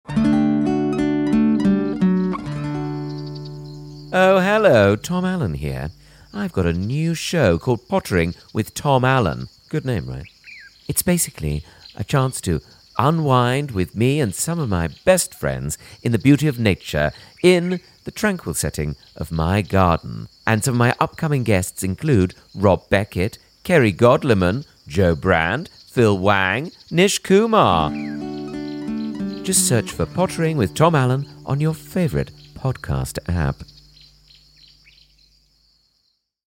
Join Tom Allen as he invites you into his garden for a chat and a potter with some very special guests.